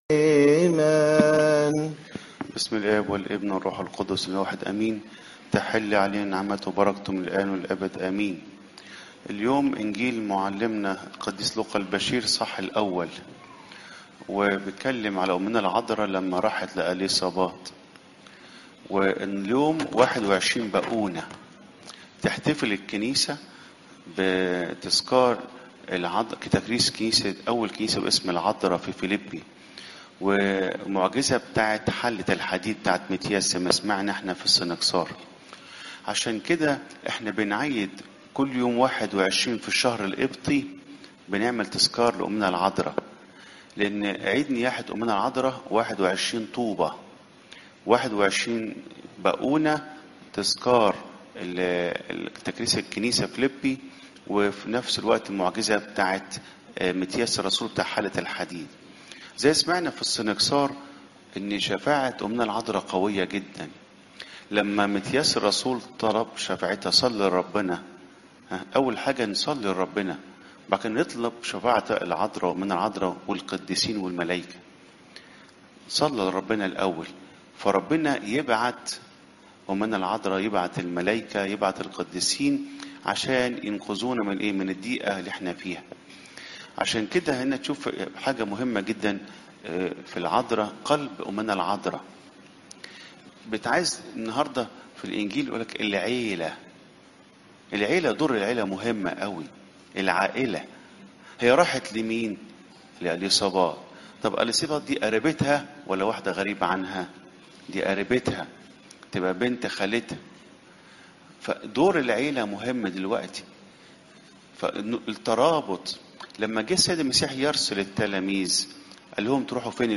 عظات المناسبات